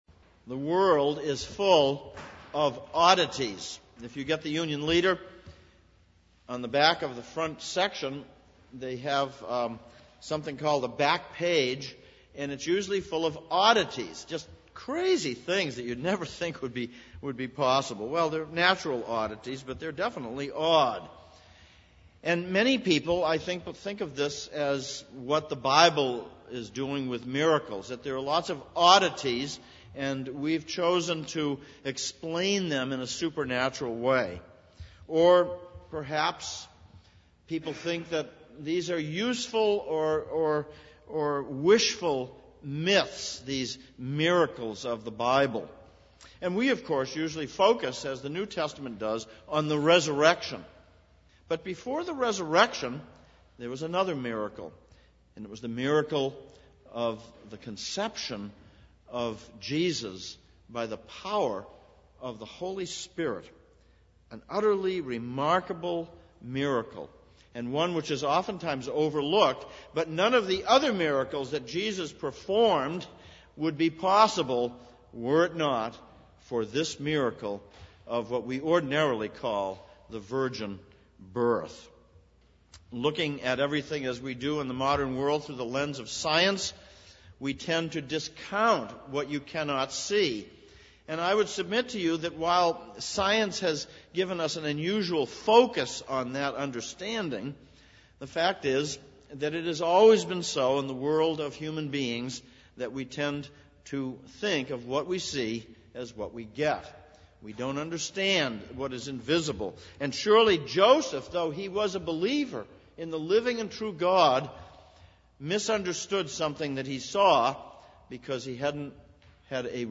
Christmas Sermons
Isaiah 7:1-25 Service Type: Sunday Morning Christmas Message 2011 « Education